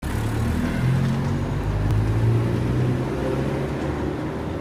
CAR_SOUND_EFFECT.mp3